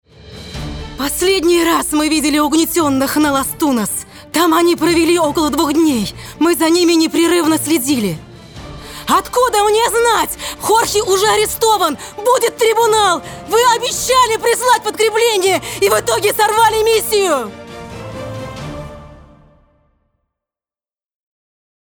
Обладательница лирико-драматического сопрано с диапазон более четырёх октав.
ЗАКАДР